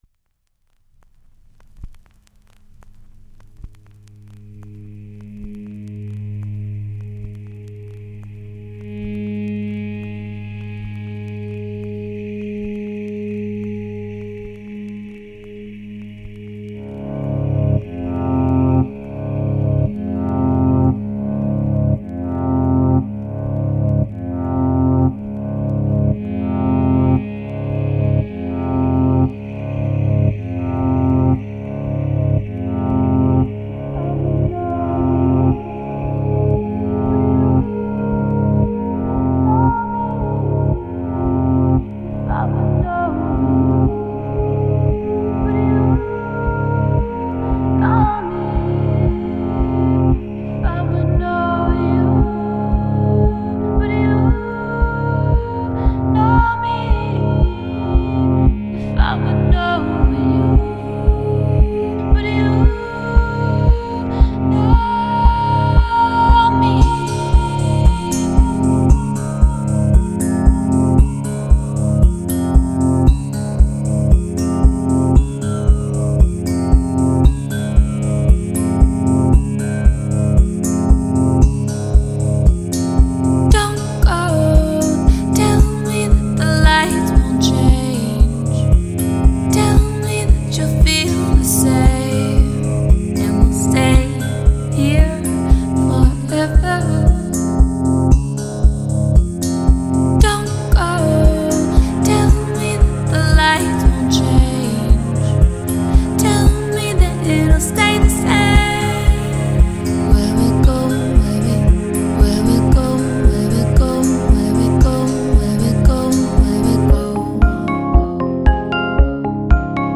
soothing tender tune
guest vocalist